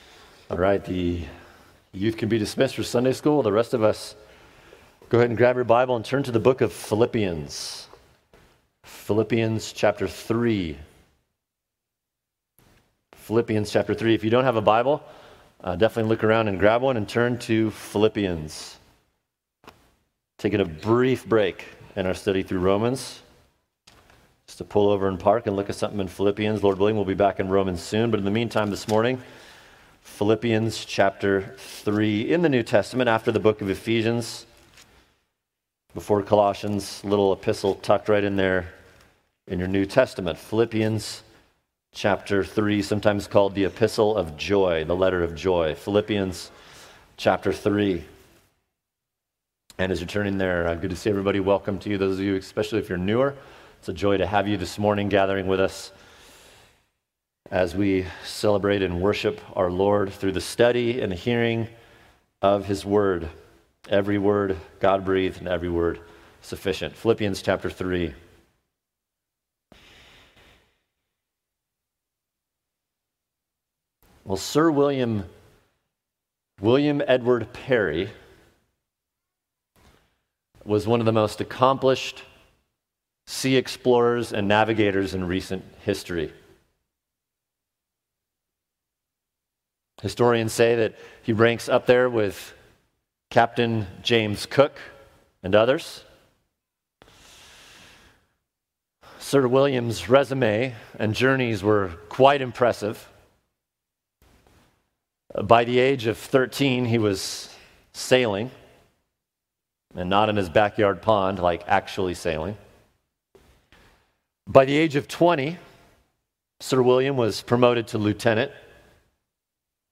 [sermon] What Does and Does not Save Philippians 3:4-9 | Cornerstone Church - Jackson Hole